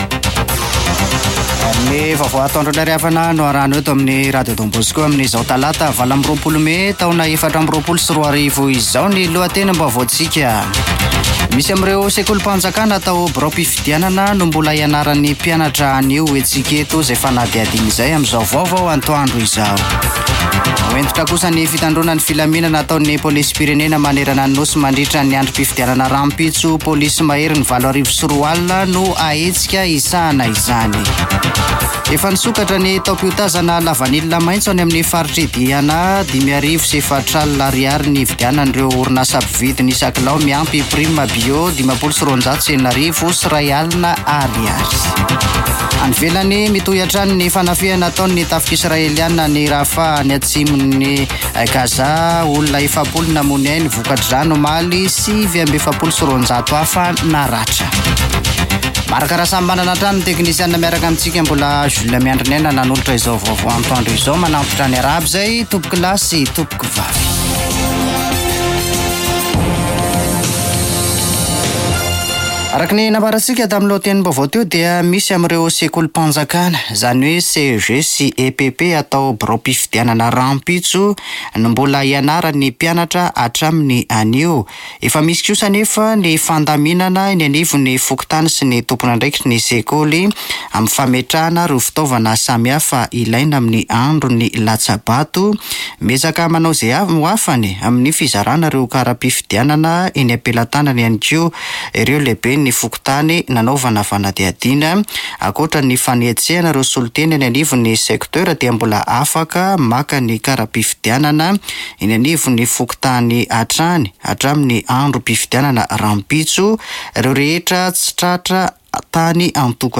[Vaovao antoandro] Talata 28 mey 2024